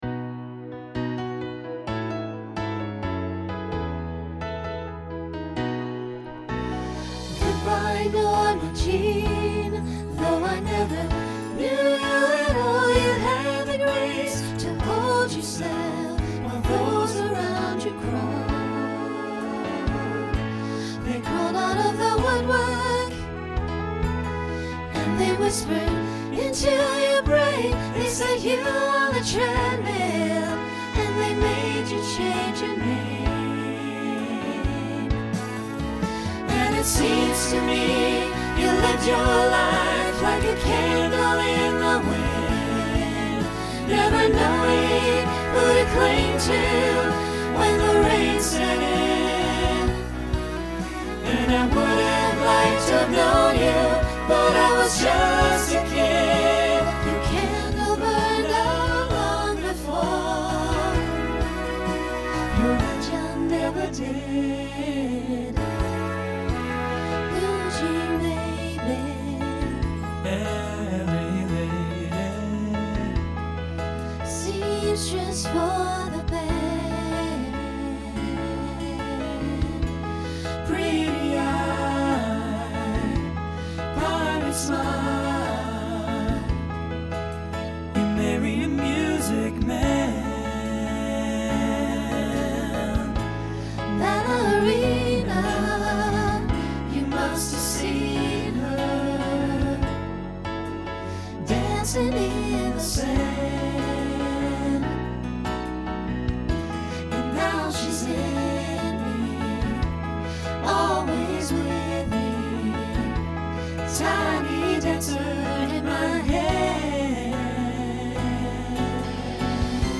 Voicing SATB Instrumental combo Genre Pop/Dance
Show Function Ballad